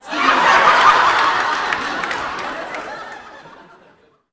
Audience Laughing-02.wav